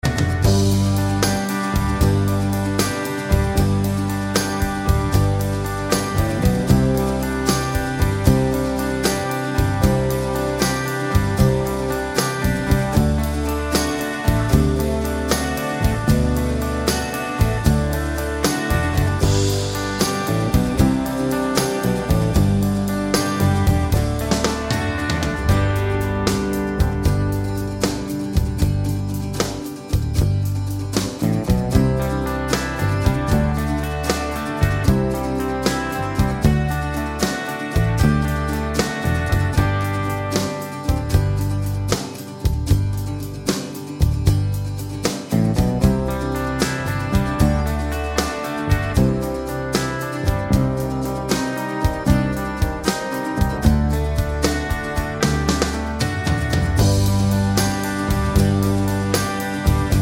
No Vocals At All Pop (1980s) 4:16 Buy £1.50